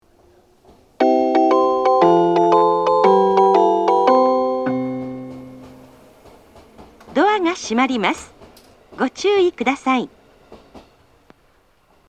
発車メロディー
スイッチを一度扱えばフルコーラス鳴ります。
2番線の走行音が被っています・・・。